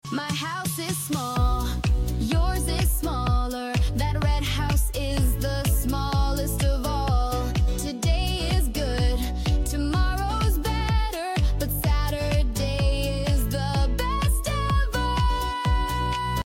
🎶 From big, bigger, biggest to good, better, best, this fun grammar song will help you master how to compare things in English while singing along!
Fun rhythm keeps you motivated